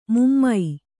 ♪ mummai